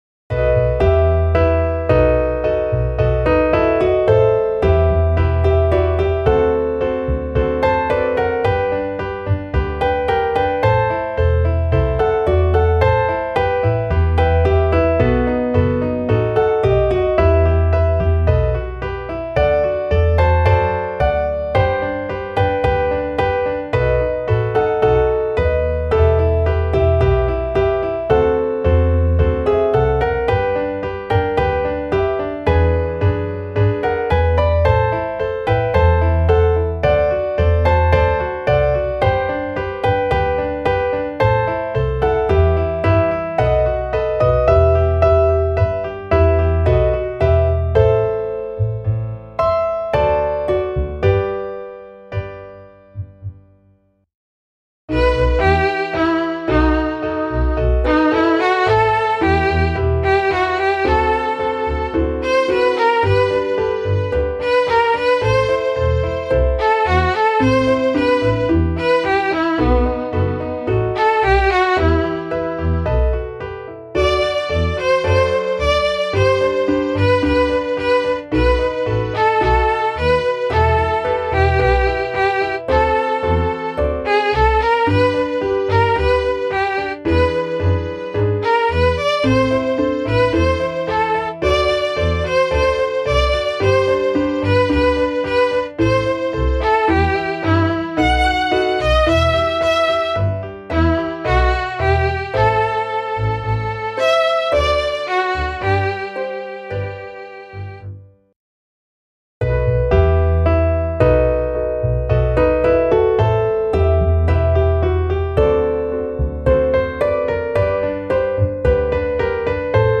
[ Ballade ]
Klaviersatz